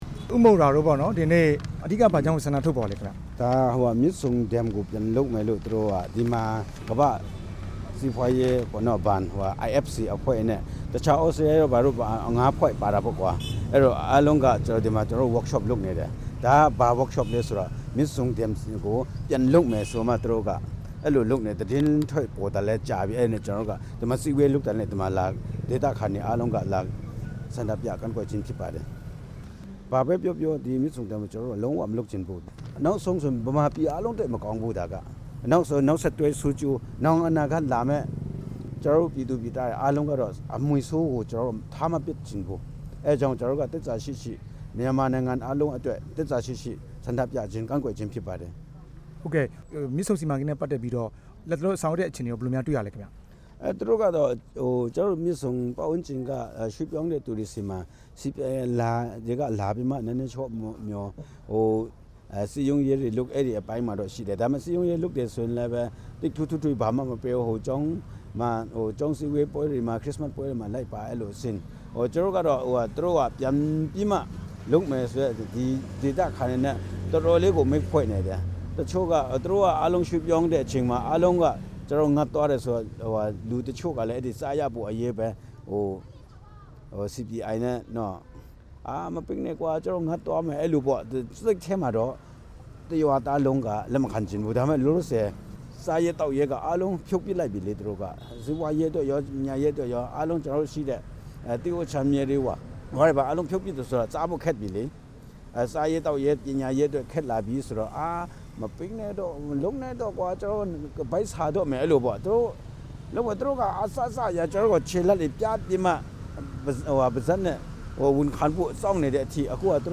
တွေ့ဆုံမေးမြန်းထားပါတယ်